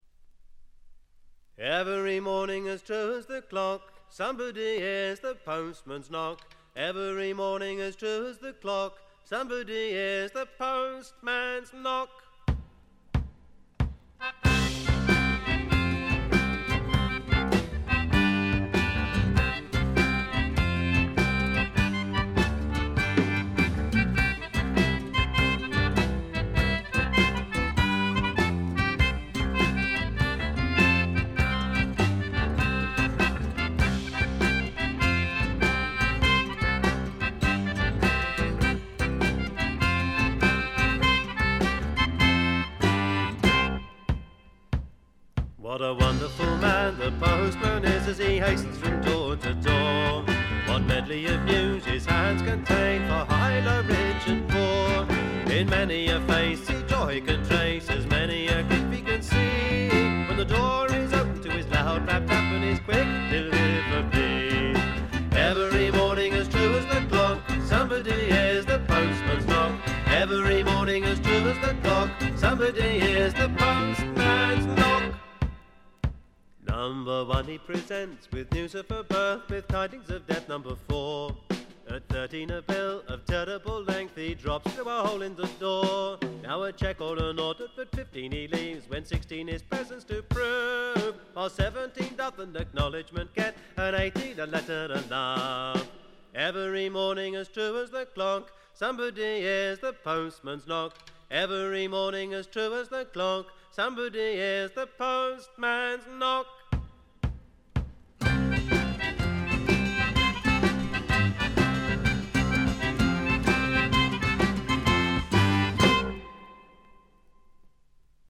部分試聴ですが、わずかなノイズ感のみ、良好に鑑賞できると思います。
1st同様に豪華メンバーによる素晴らしいエレクトリック・トラッドです。
試聴曲は現品からの取り込み音源です。